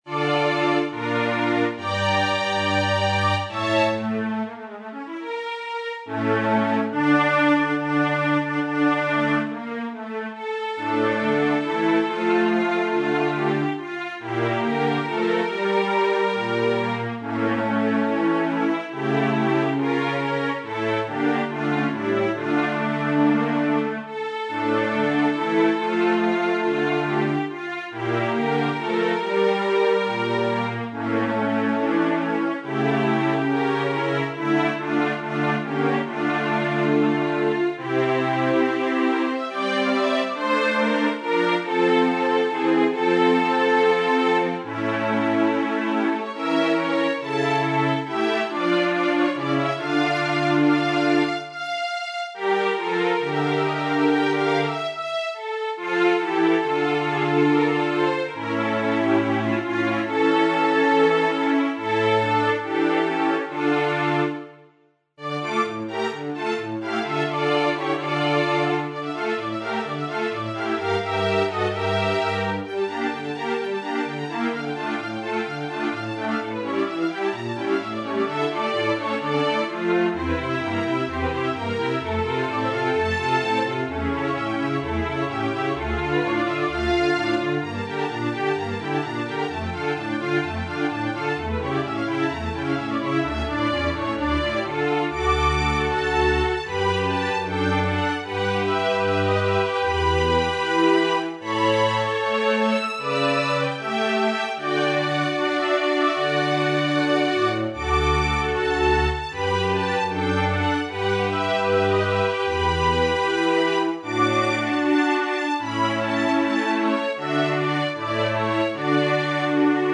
quarteto